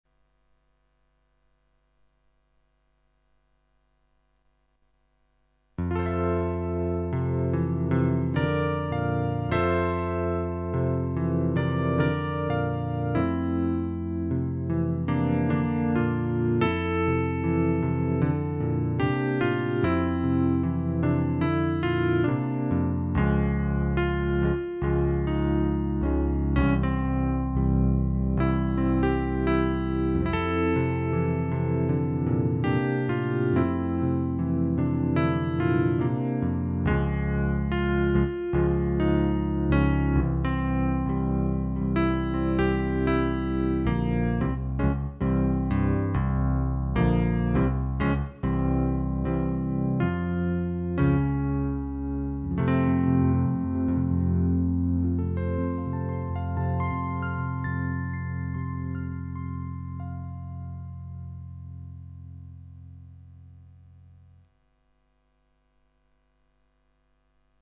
cp80_gem.mp3